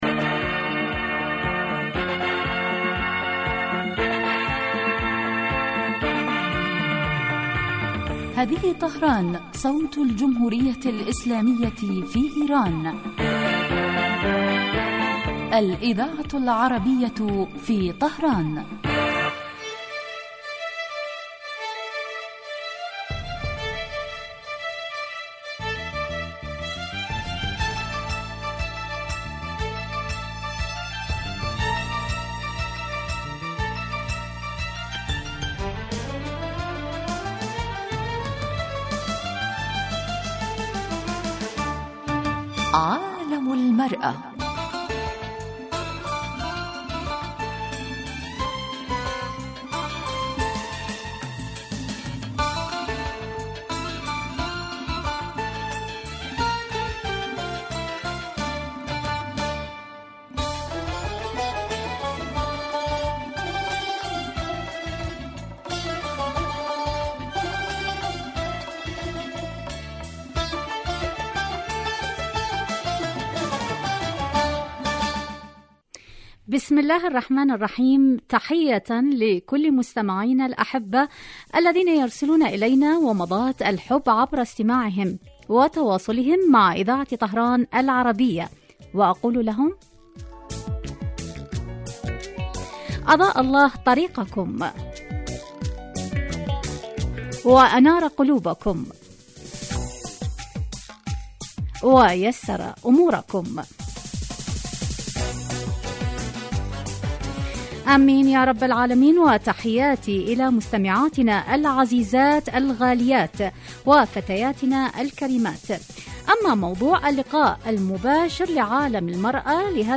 من البرامج الناجحة في دراسة قضایا المرأة فی العالمین الإسلامي و العربي ومعالجة ما لها من مشکلات و توکید دورها الفاعل في تطویر المجتمع في کل الصعد عبر وجهات نظر المتخصصین من الخبراء و أصحاب الرأي مباشرة علی الهواء